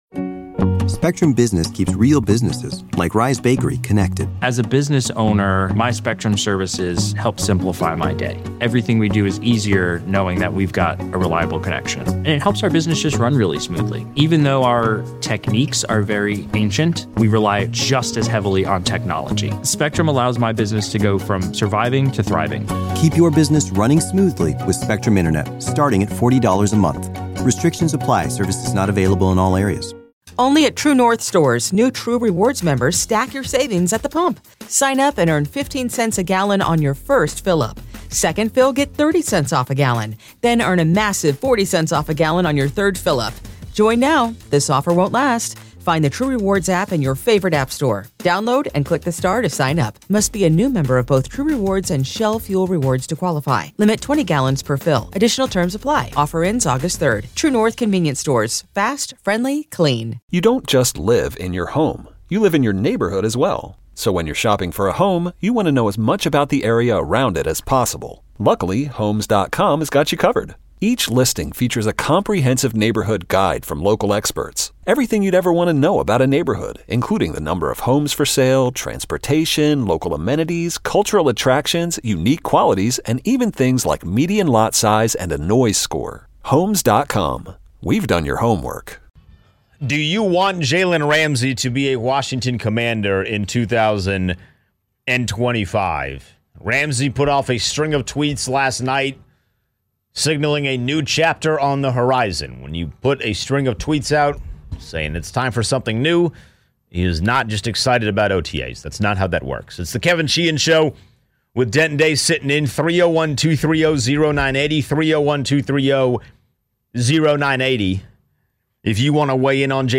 5.29.25, Callers give their thoughts on if they want the Commanders to go after Jalen Ramsey in a trade scenario.